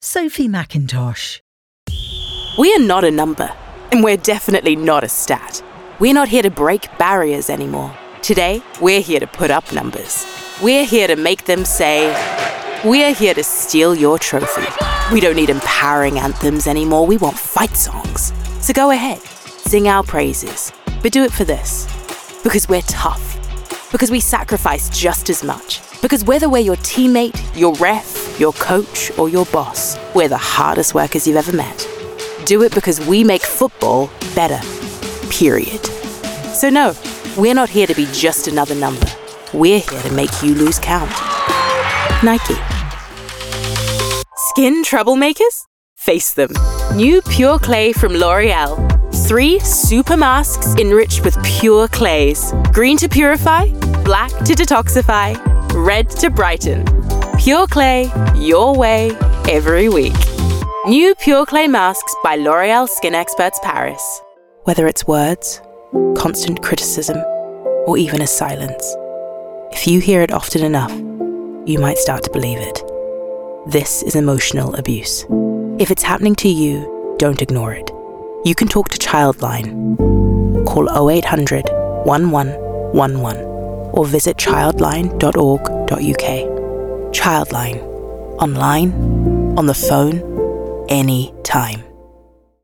Description: New Zealand: reassuring, smooth, contemporary
Age range: 20s - 30s
American, Australian, Irish (Northern), London, New Zealand*, RP